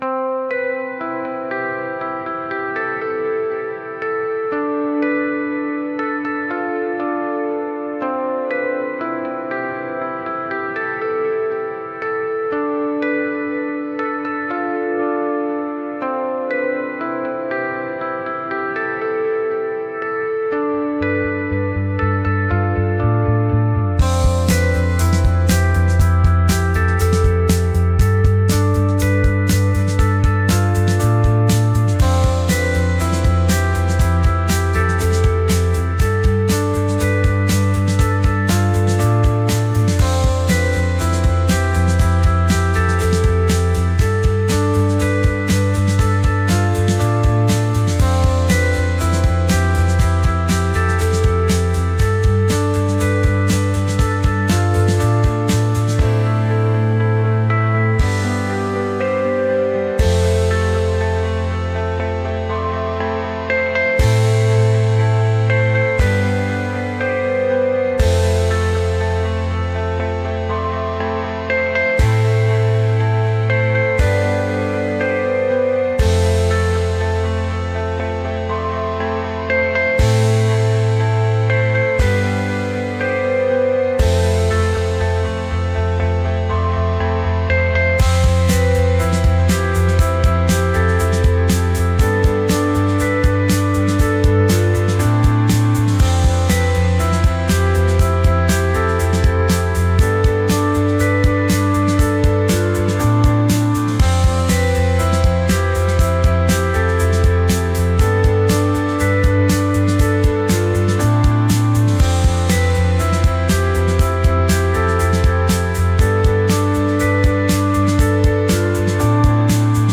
Shoegaze-Pitch-Outs-Concert-Piano-Cover.mp3